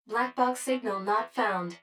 153_Box_Signal_Not_Found.wav